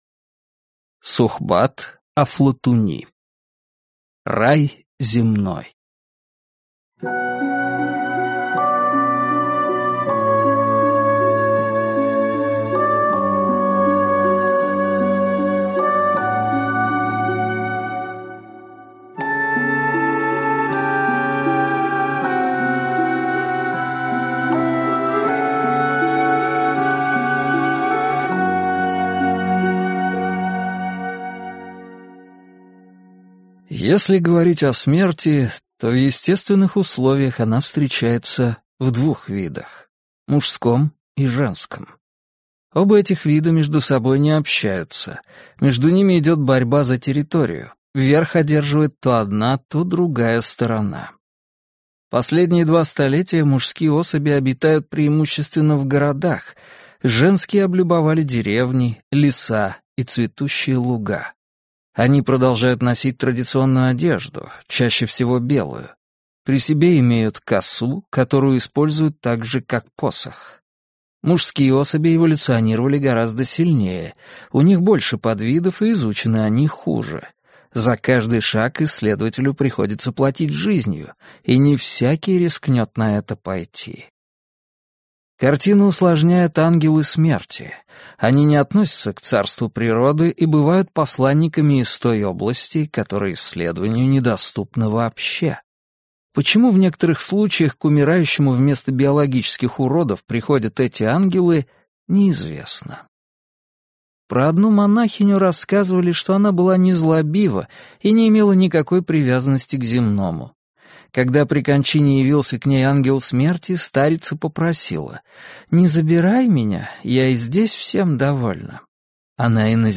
Аудиокнига Рай земной | Библиотека аудиокниг